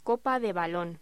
Locución: Copa de balón
voz